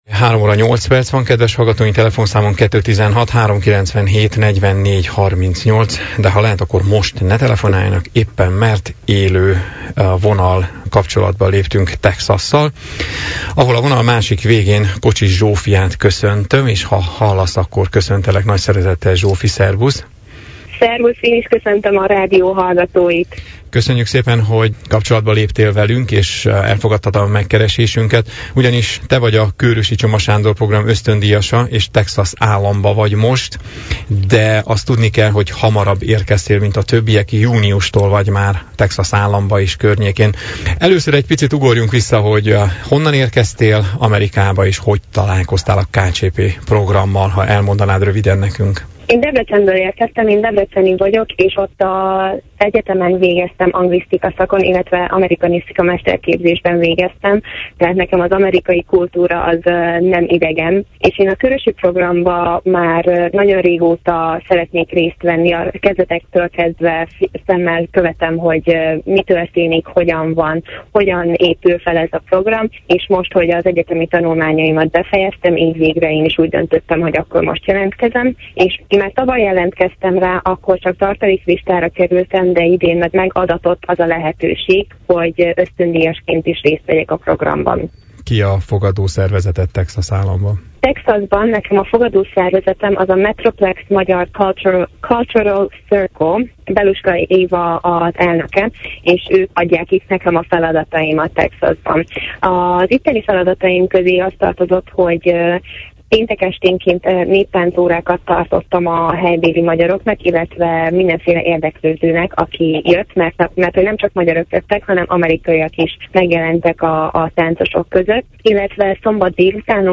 telefonon keresztül